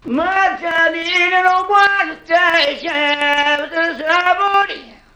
Una flauta callejera del presente resistente y una Cantante Ambulante del pasado perdida en la época de la Lira Italiana se encuentran, coinciden en Mib menor Dórico afinados a 432Hz y generan las 2 melodías temáticas (fragmentos temáticos rudimental)